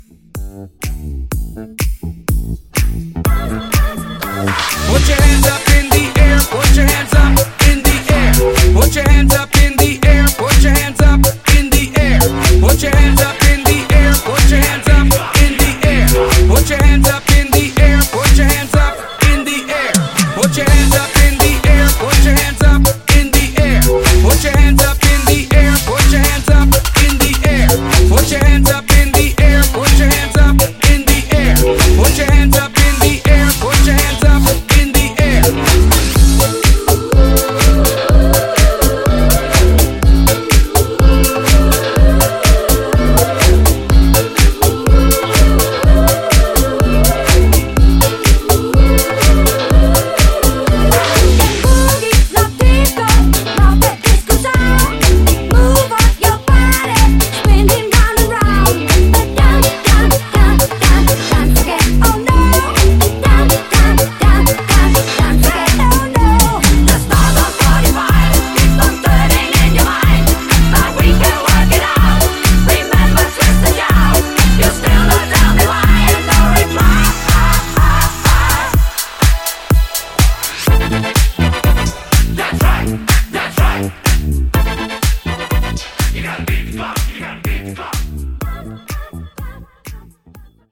BOOTLEG , MASHUPS , PARTY BREAKS 128 Clean